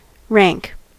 Ääntäminen
IPA : /ɹæŋk/